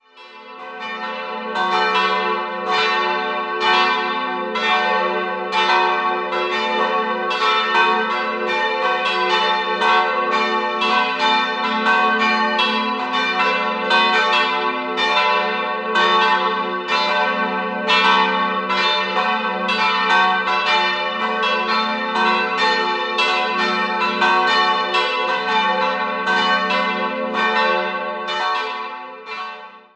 3-stimmiges TeDeum-Geläute: fis'-a'-h' Die kleine Marienglocke stammt aus dem Jahr 1921, die beiden größeren sind dem Hl. Bartholomäus (fis'+2) und dem Heiligen Antonius (a'+ 2) geweiht wurden 1950 bei Johann Hahn in Landshut gegossen.